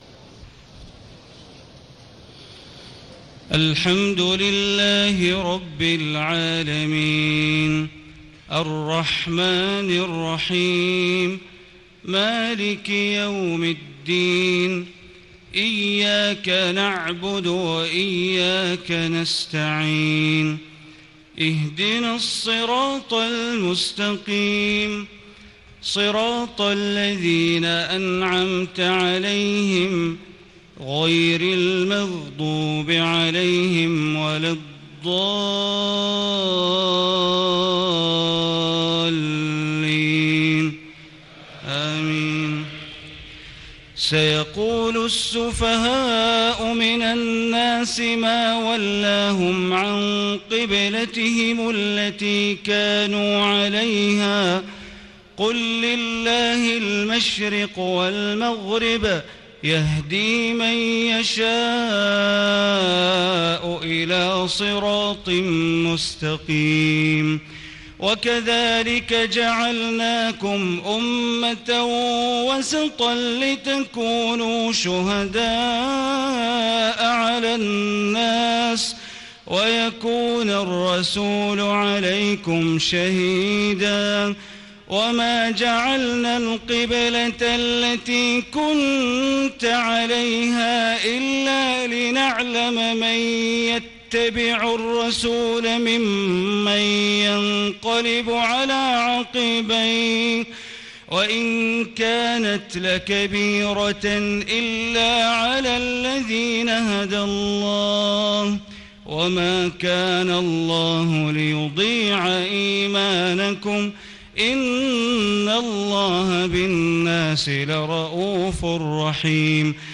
تهجد ليلة 22 رمضان 1436هـ من سورة البقرة (142-218) Tahajjud 22 st night Ramadan 1436H from Surah Al-Baqara > تراويح الحرم المكي عام 1436 🕋 > التراويح - تلاوات الحرمين